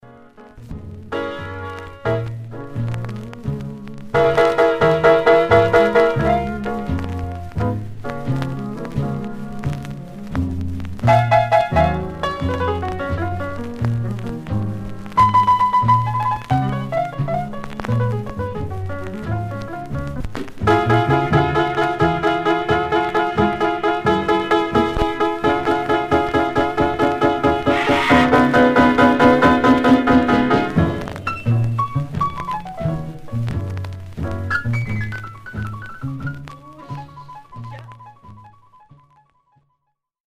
Some surface noise/wear
Mono
R&B Instrumental